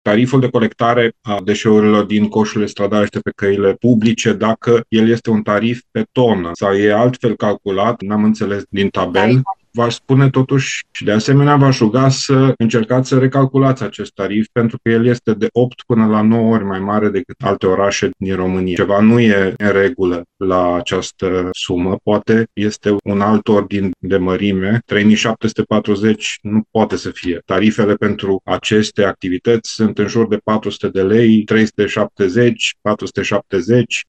Consilierul liberal Dan Diaconu a cerut lămuriri despre modul în care au fost calculate taxele, susținând că prețul este mult prea mare.